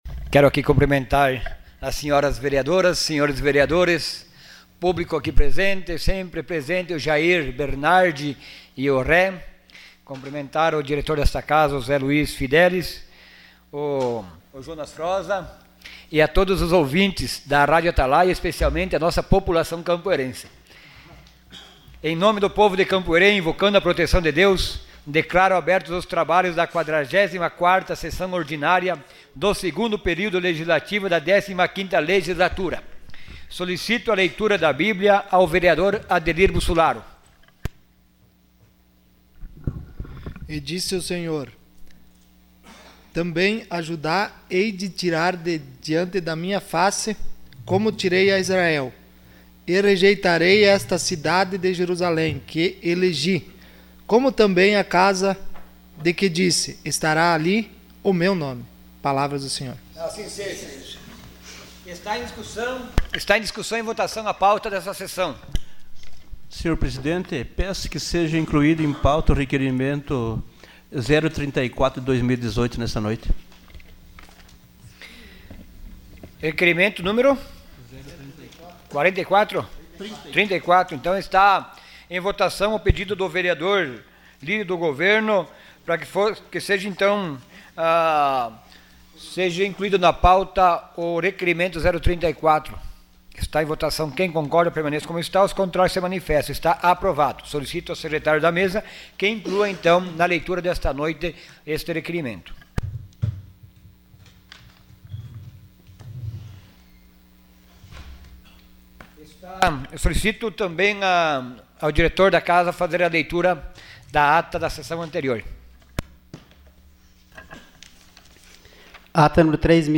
Sessão Ordinária dia 24 de setembro de 2018.